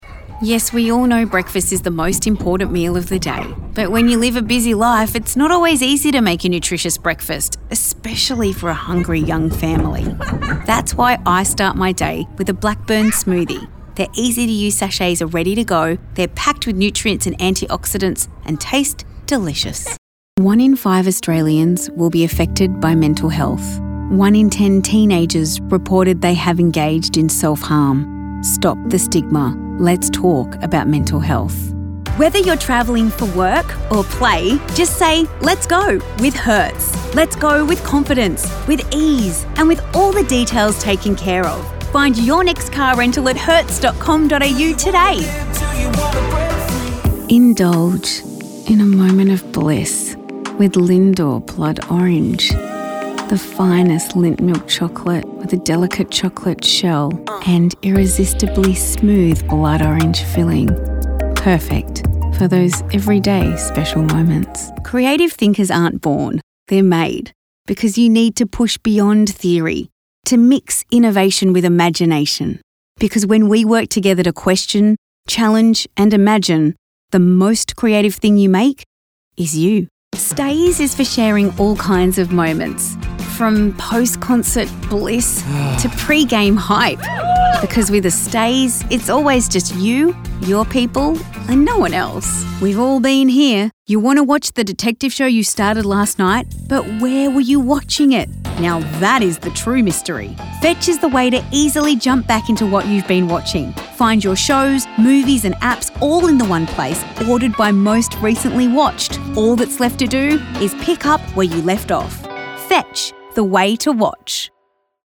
Never any Artificial Voices used, unlike other sites.
Adult (30-50)